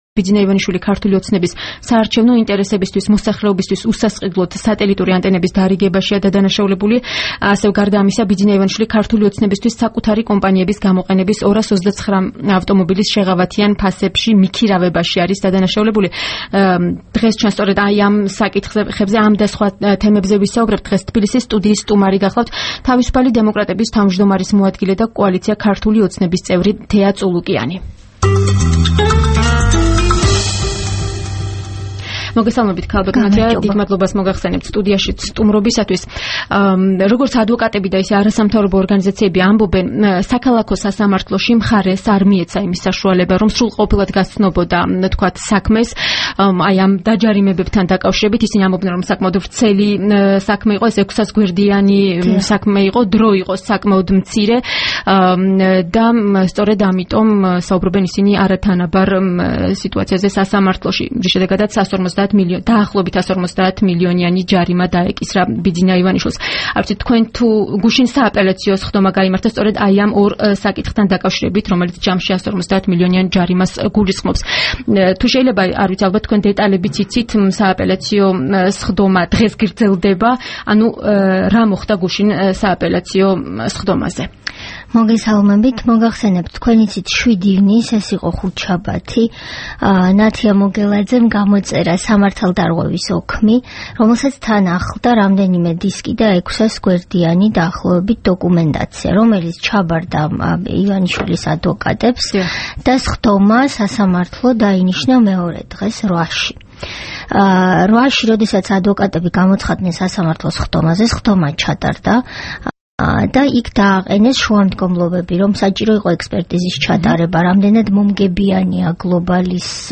რადიო თავისუფლების თბილისის სტუდიის სტუმარი იყო კოალიცია „ქართული ოცნების“ წევრი თეა წულუკიანი.
საუბარი თეა წულუკიანთან